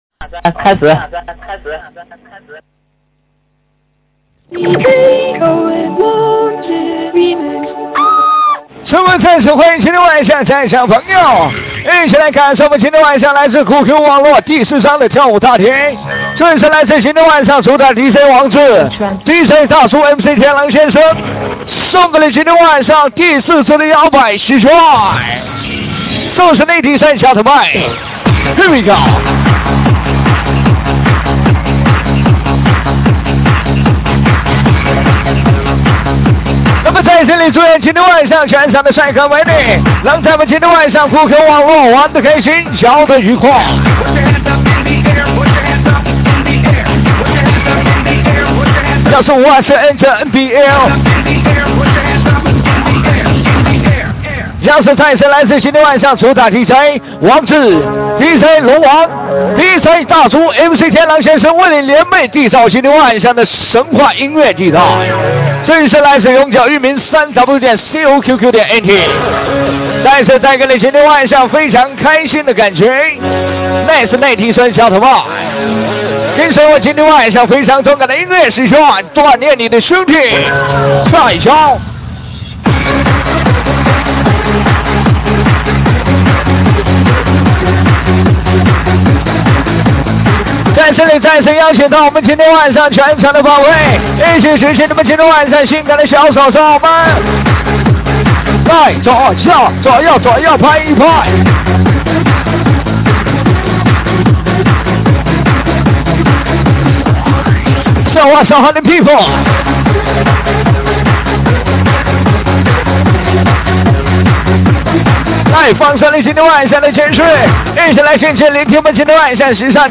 MC喊麦